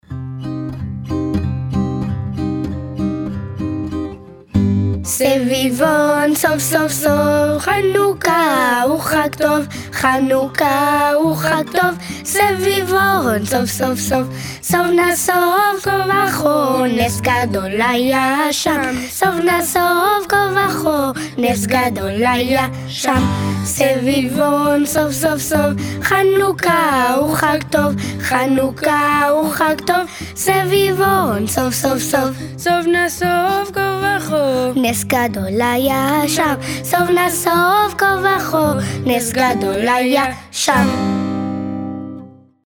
Cette chanson est traditionnellement chantée à ‘Hanouka.
Audio Enfants: